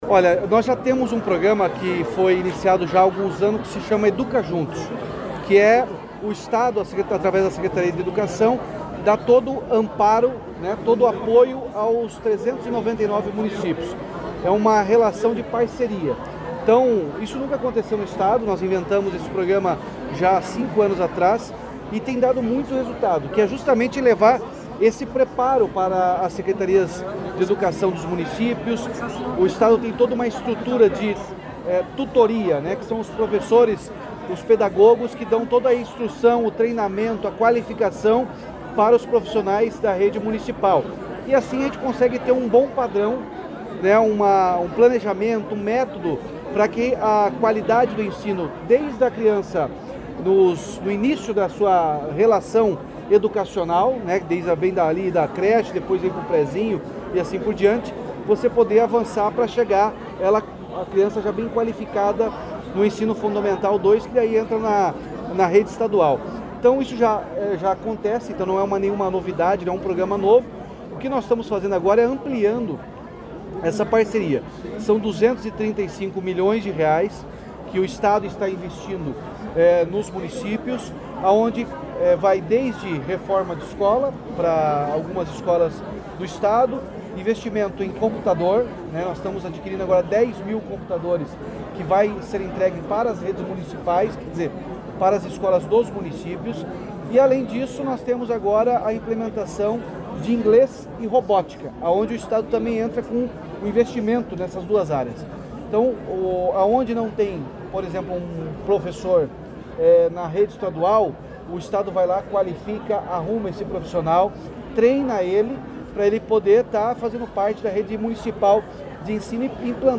Sonora do governador Ratinho Junior sobre o pacote de investimentos na área da educação